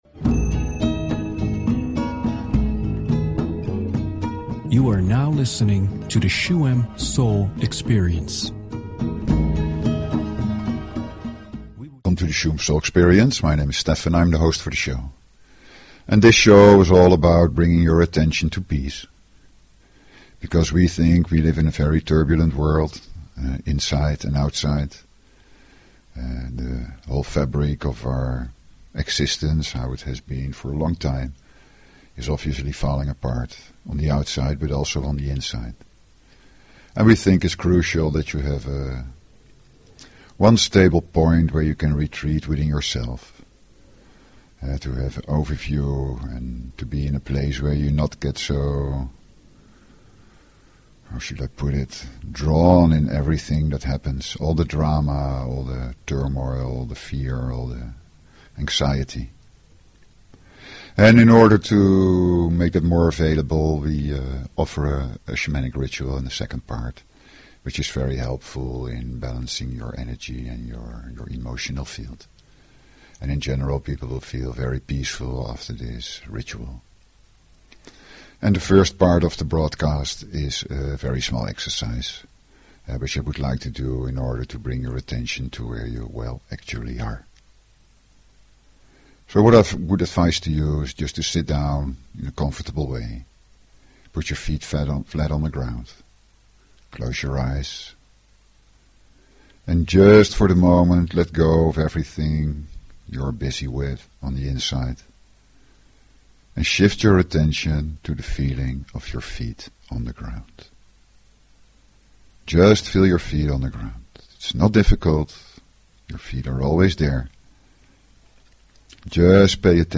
Talk Show Episode, Audio Podcast, Shuem_Soul_Experience and Courtesy of BBS Radio on , show guests , about , categorized as
Shuem Soul Experience is a radio show with:
The second part of the show is a shamanic healing meditation with drum, rattle and chant altered with (short) periods of silence.